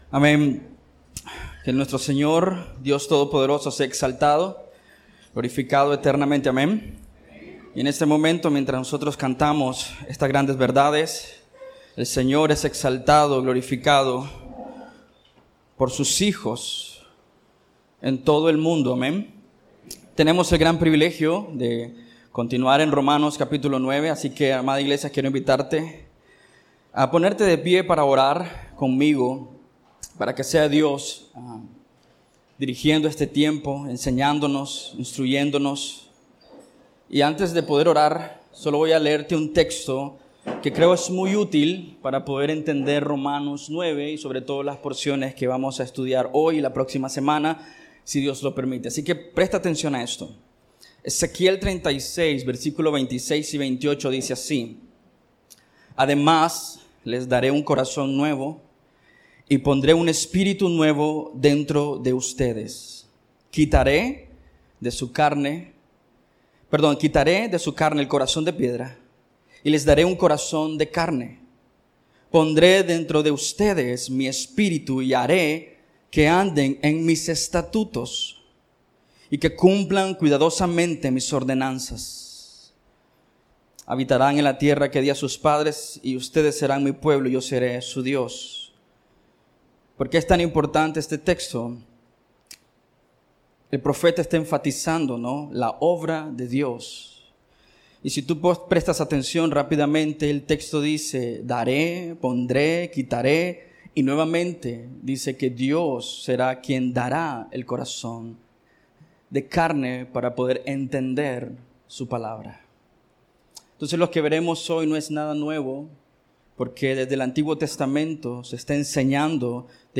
Escucha la prédica del domingo